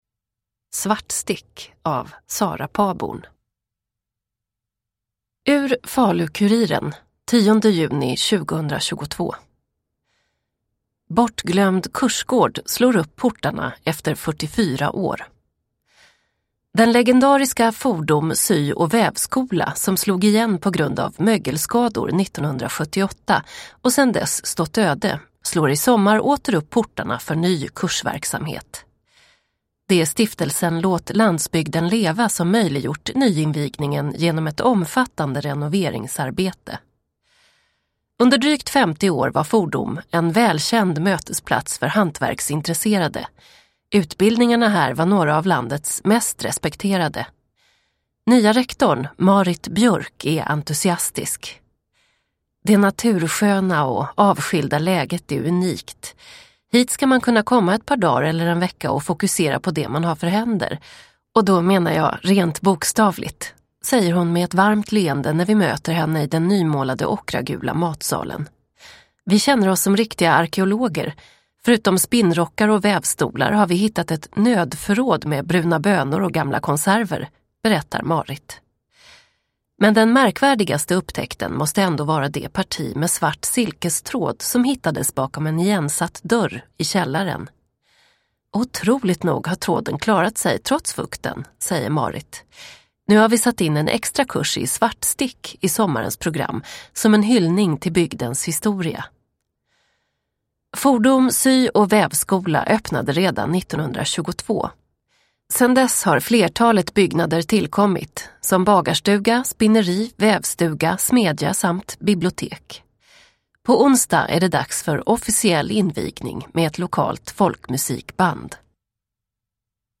Svartstick – Ljudbok – Laddas ner